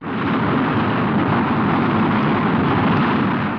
دانلود آهنگ طیاره 8 از افکت صوتی حمل و نقل
دانلود صدای طیاره 8 از ساعد نیوز با لینک مستقیم و کیفیت بالا
جلوه های صوتی